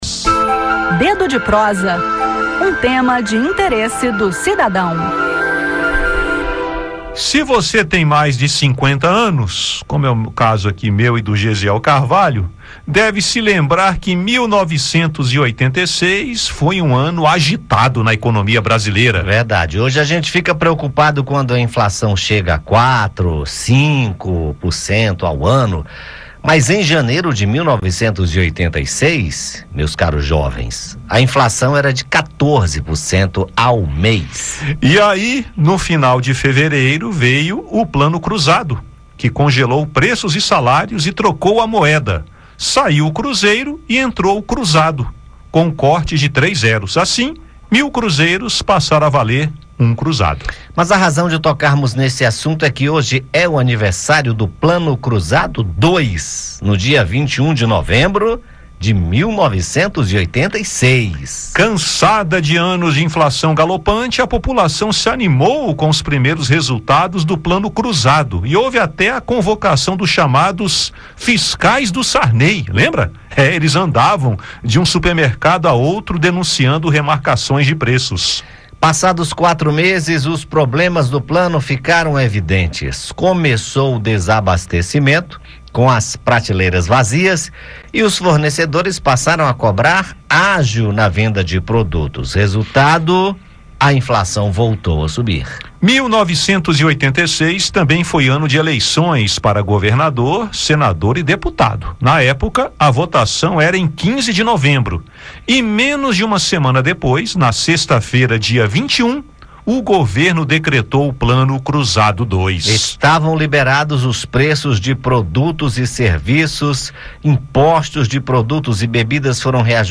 Um deles foi o Plano Cruzado 2, lançado no dia 21 de novembro de 1986, trazendo alguma esperança aos brasileiros. Ouça no bate-papo como essa e outras medidas foram criadas e, em alguns casos, acabaram por gerar perdas para quem tinha recursos aplicados.